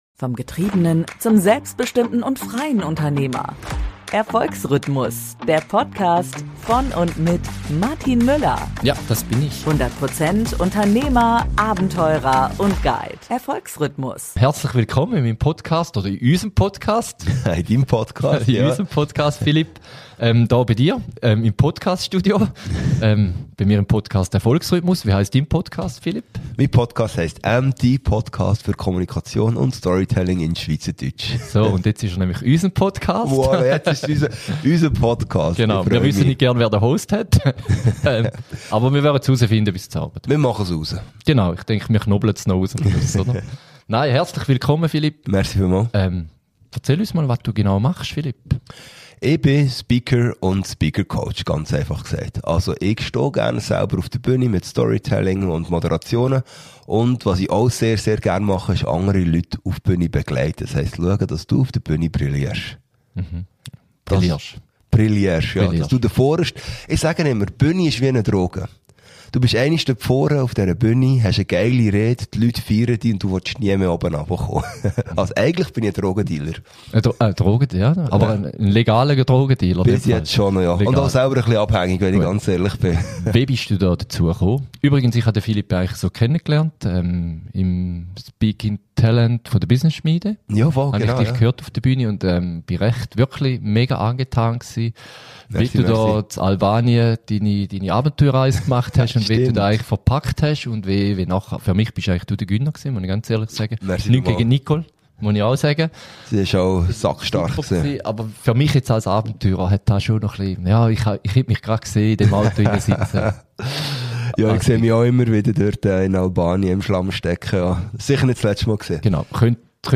über die Kunst des Storytellings und authentischer Bühnenpräsenz auf Schweizerdeutsch.